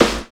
34 SNARE.wav